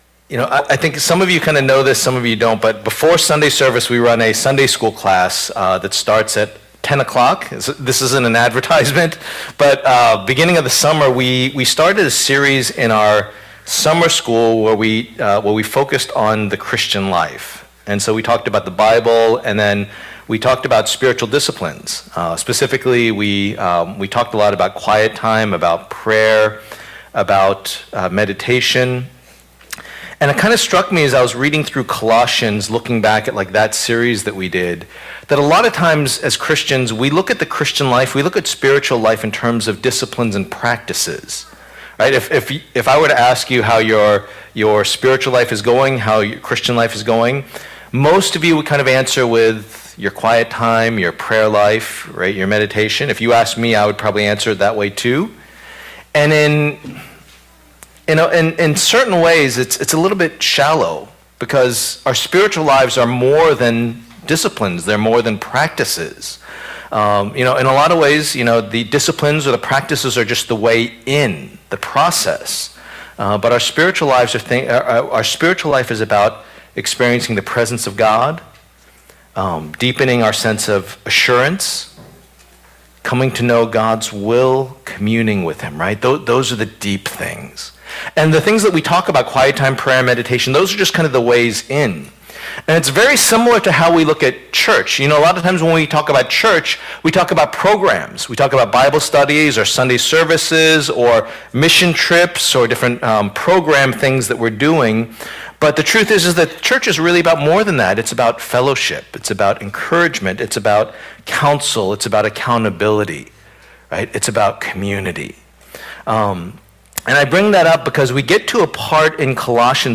Passage: Colossians 3:1-8 Service Type: Lord's Day